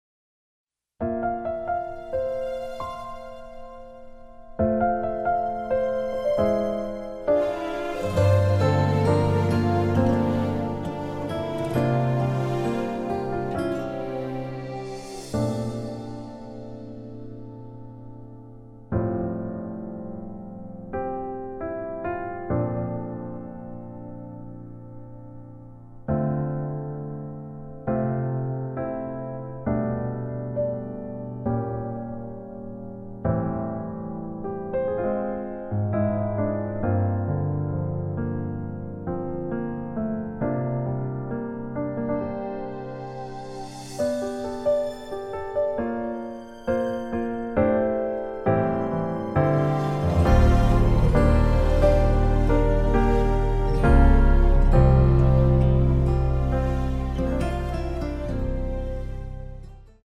공식 음원 MR
앞부분30초, 뒷부분30초씩 편집해서 올려 드리고 있습니다.
중간에 음이 끈어지고 다시 나오는 이유는